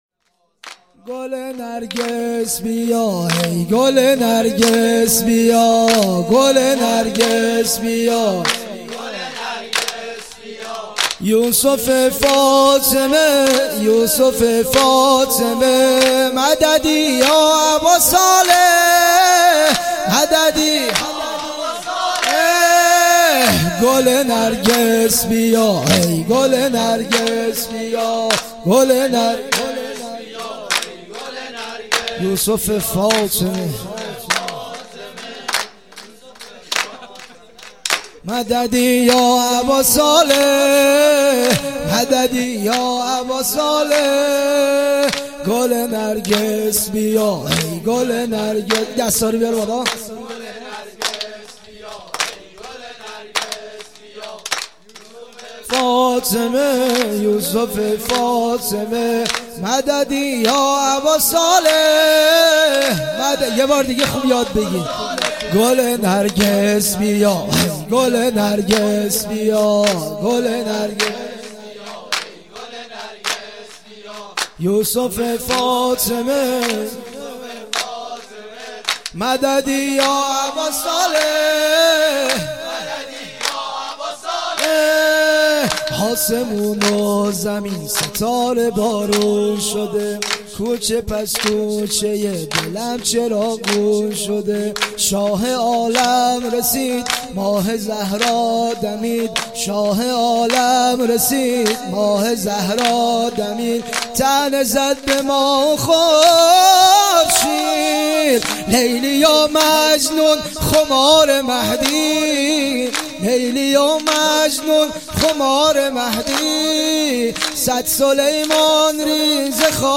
جشن نیمه شعبان، 97.2.13